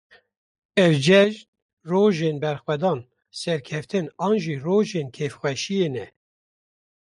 /bɛɾxwɛˈdɑːn/